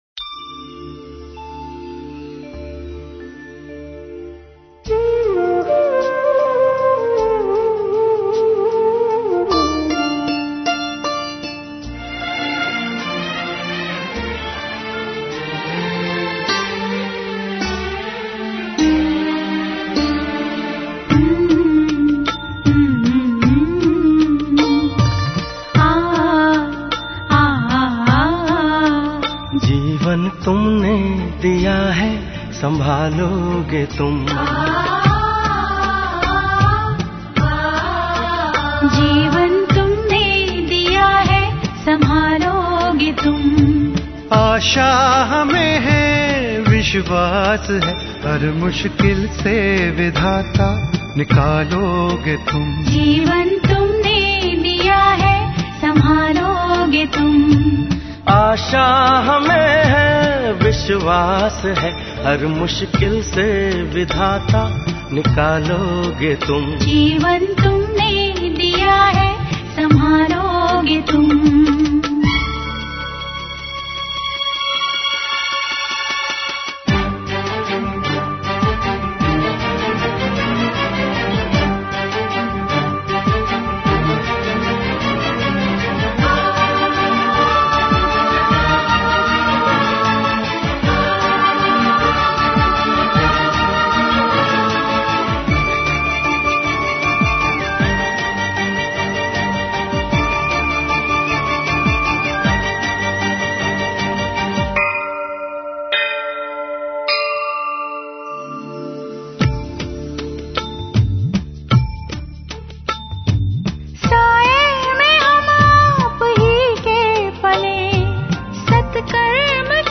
Samay Ke Bahaav Mein Tutata Santulan Ka Pul समय के बहाव में टूटता संतुलन का पुल (Online Class